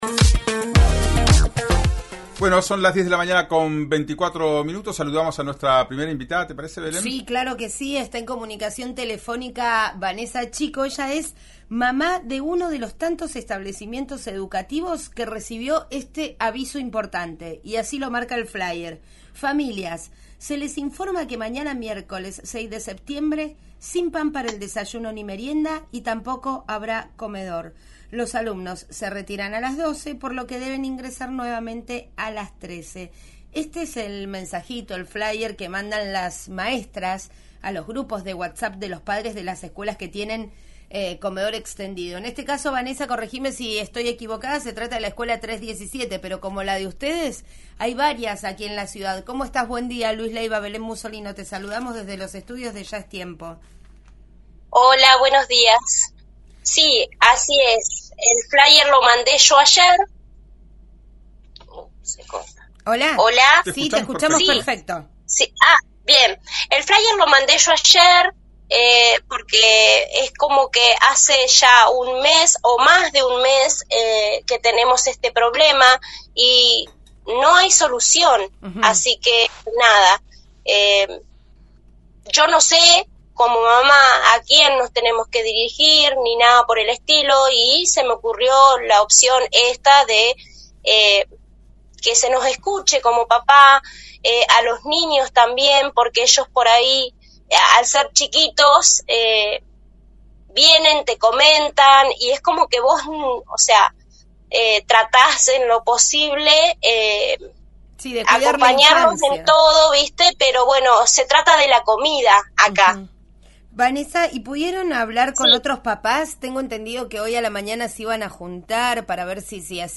madres de la escuela 317 de Roca en RÍO NEGRO RADIO